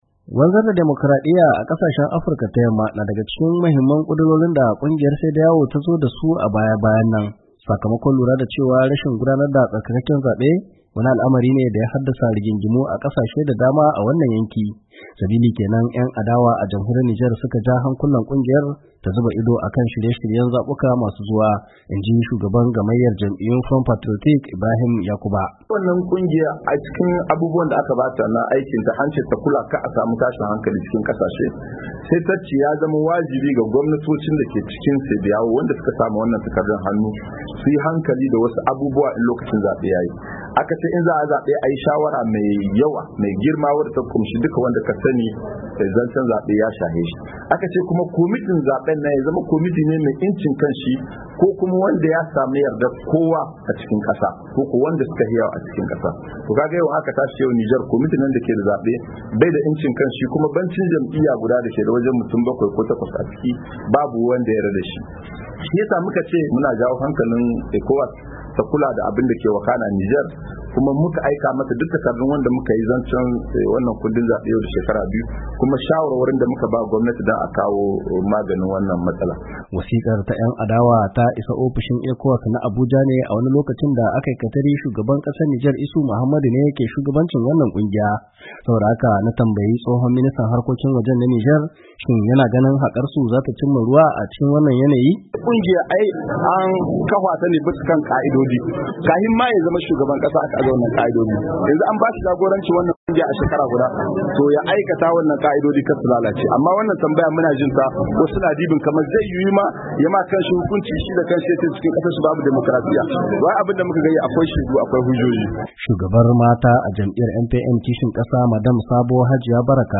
Ga Cikakken Rahoto